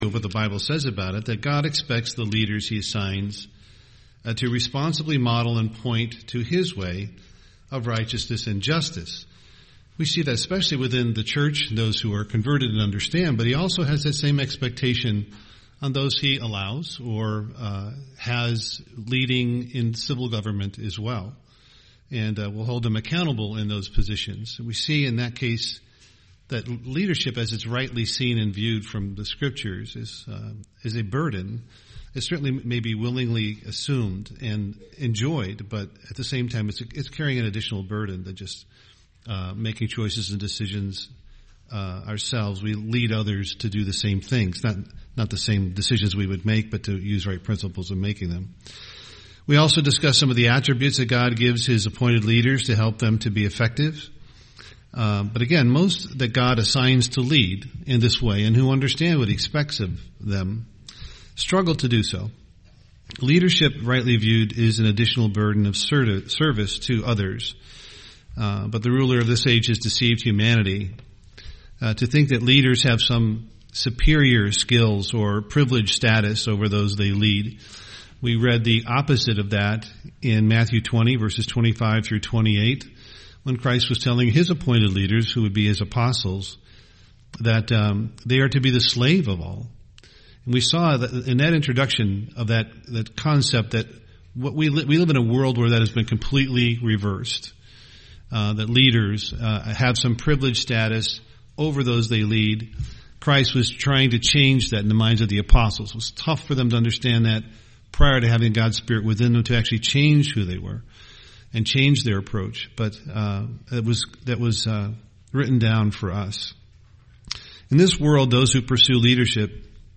UCG Sermon submission Studying the bible?